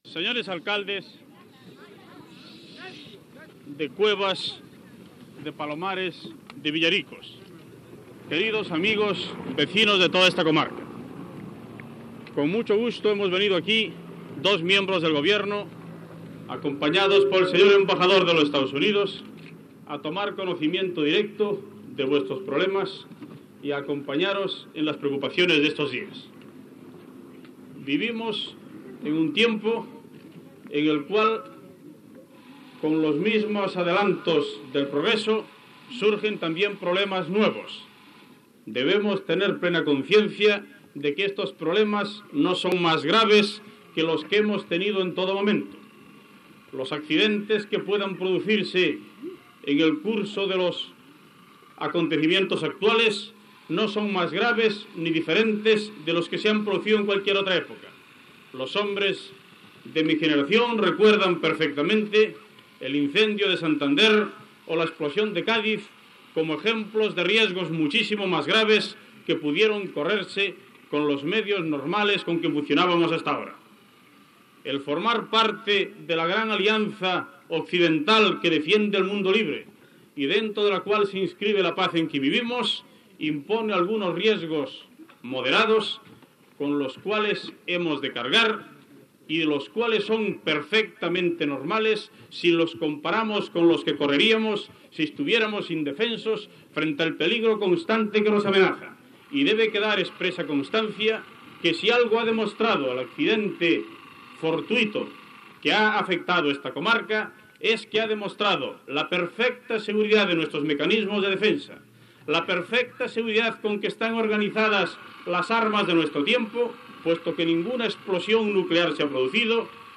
Discurs del ministre d'informació i turisme Manuel Fraga Iribarne unes setmanes deprés que un acceident d'avions dels EE.UU. provoqués la caiguda de quatre bombes termonuclears a la platja de Palomares
Informatiu